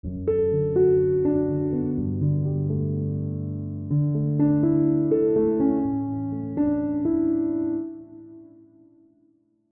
Помогите найти такое готовое dream piano
Нуу... это вроде как просто глубоко отфильтрованное\эквализованное обычное пиано+pad.. параметры фильтра\эквалайзера и исходник пиано с падом можно подбирать до бесконечности.
Pad прилепить поленился: Вложения Dream Piano Giant.mp3 Dream Piano Giant.mp3 189,6 KB · Просмотры: 452